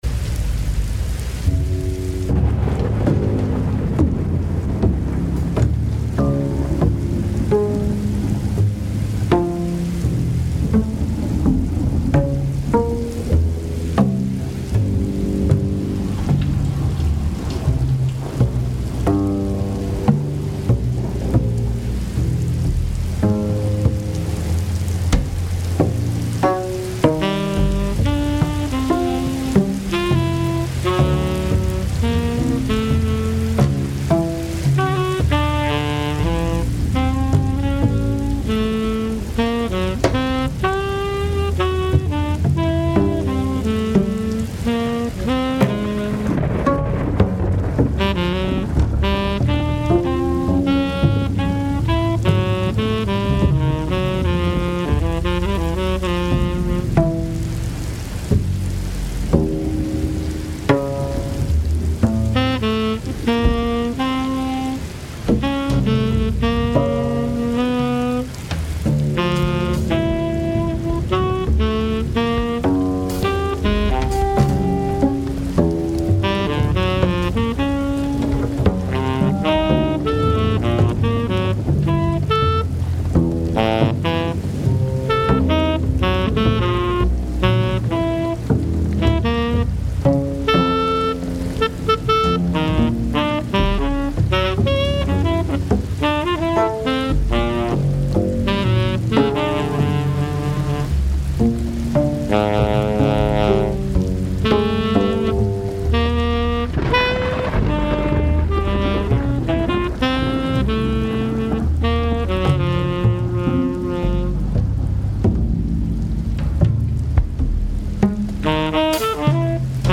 which live streamed on 30 May 2025
Live Music, Saxophone